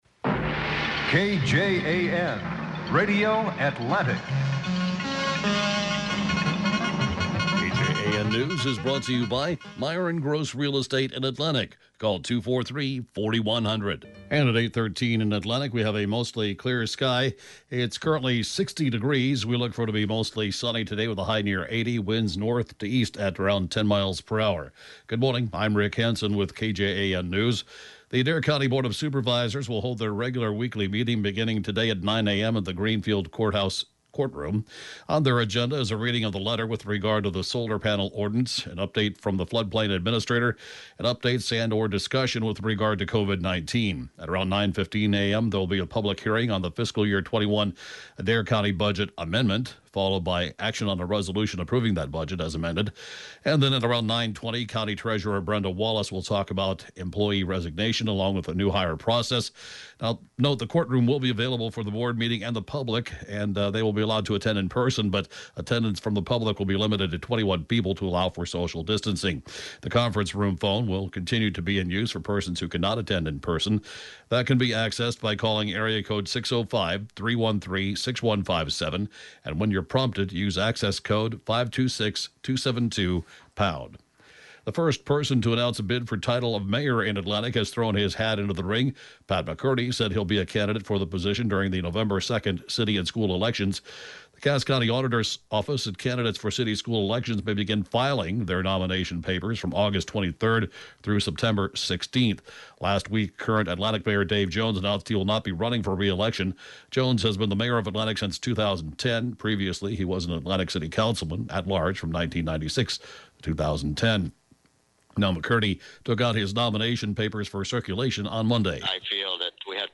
(8a.m. Newscast)